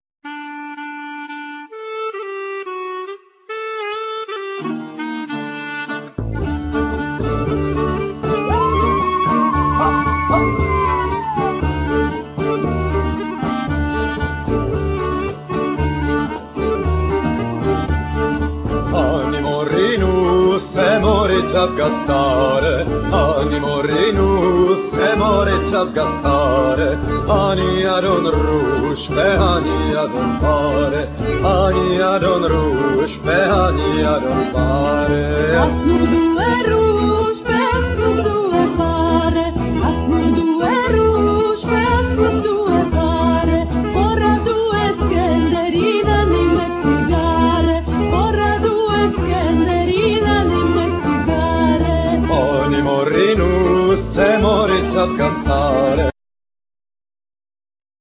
Flute,Percussions,Gittern,Vocals
Gittern,Saz,Tarabuka,Vocals
Five strings fiddle,Percussions,Vocal
Soprano fiddle,Tarabuka,Vocal
Davul,Tamburello,Trabuka,Vocal
Double bass